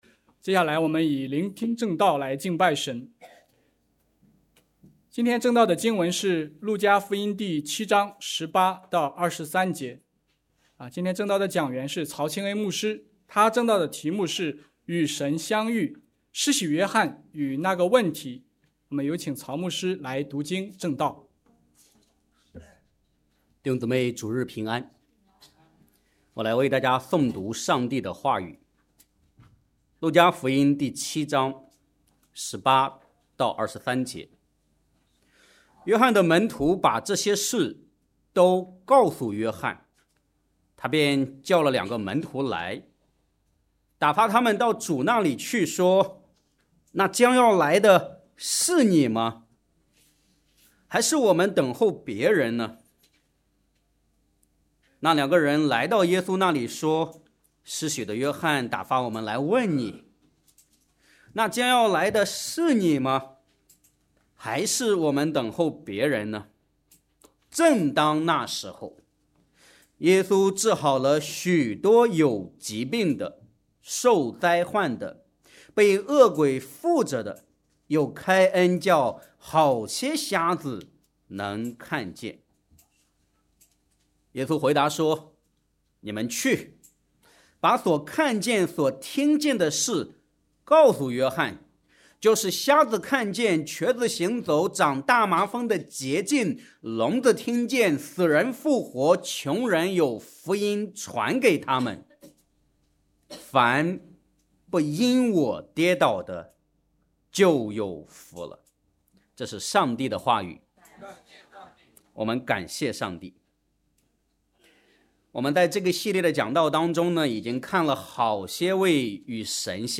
Home / 证道 / 与神相遇 / 与神相遇：施洗约翰与那个问题